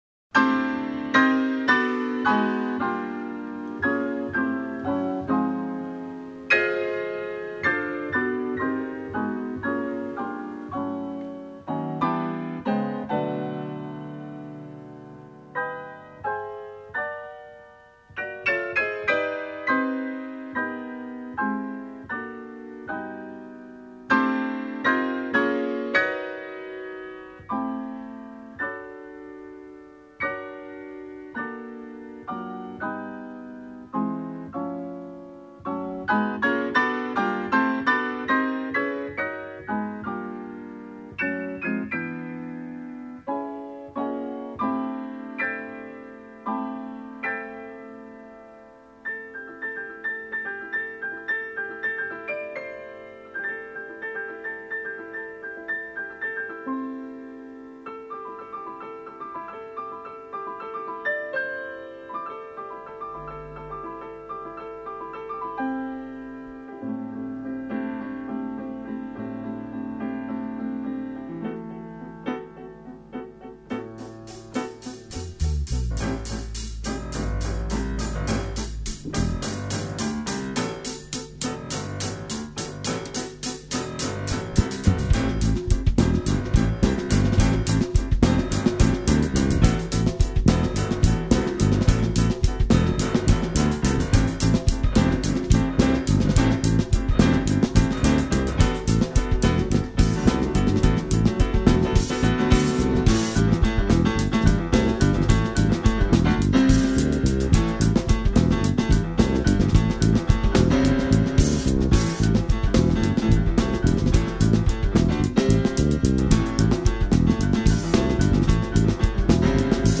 MUSIC FROM LIVE JAZZ FESTIVAL 2002: